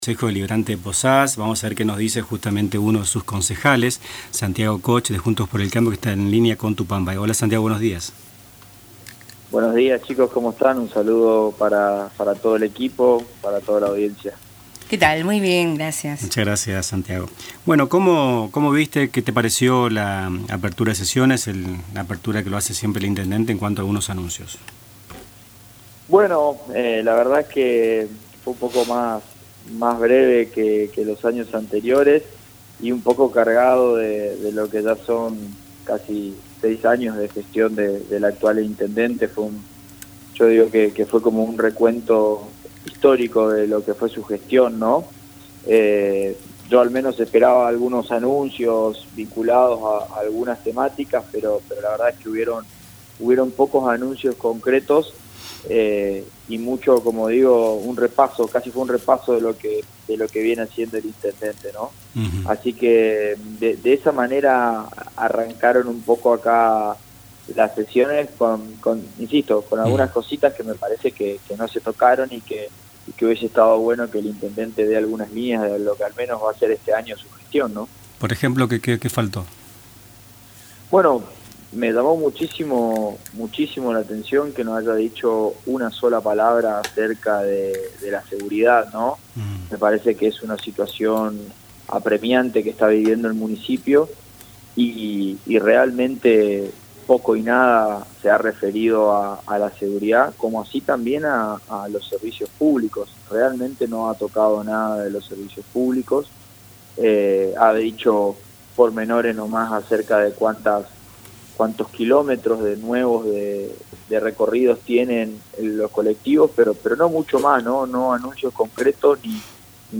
En “Nuestras Mañanas”, entrevistamos al concejal Santiago Koch (Juntos por el Cambio) del Honorable Concejo Deliberante de Posadas, quién se refirió a los anuncios del intendente de Posadas, el pasado 1 de marzo. El edil brindó su opinión sobre temas no anunciados sobre seguridad y luego, sobre la discusión de manos únicas en la ciudad de Posadas, que en breve enviará el ejecutivo municipal para su tratamiento en comisiones. Finalmente, anticipó la actividad electoral que arranca en la provincia, dentro de los plazos establecidos.